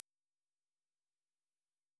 Im Hörbeispiel wird daraus ein Lo- und ein HiShelf und eine 100 Hz Bell für Snarebumms in diesem Fall.
Ich habe eine rohe Kick und Snare genommen und über die Auxwege jeweils nach Geschmack die 3 IR-Falter angefahren. Im Hörbeispiel hört man erst 2 Takte nativ, dann über 4 Takte hinweg einen FadeIn der EQ-Gruppe, dann 2 Takte mit EQ und am Ende 2 Takte wieder mit dem FX-Fader in Mute...